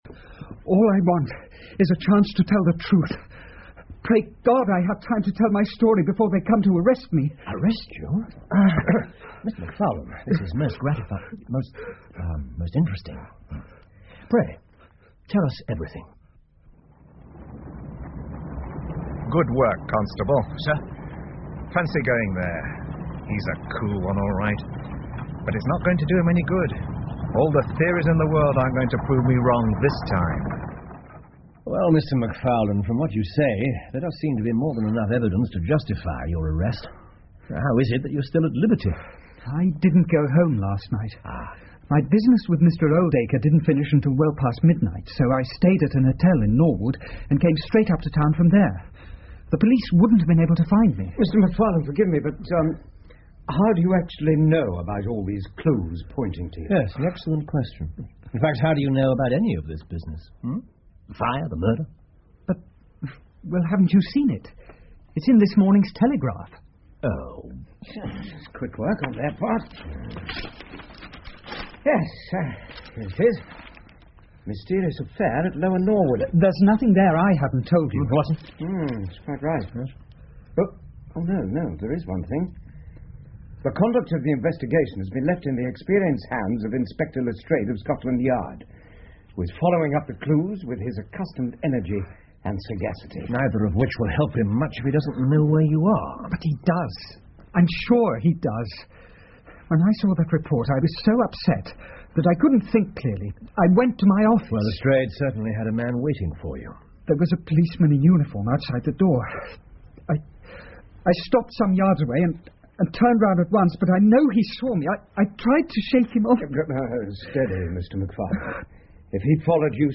福尔摩斯广播剧 The Norwood Builder 4 听力文件下载—在线英语听力室